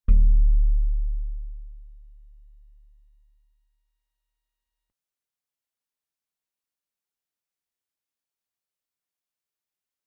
G_Musicbox-D1-mf.wav